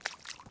Footsteps
puddle1.ogg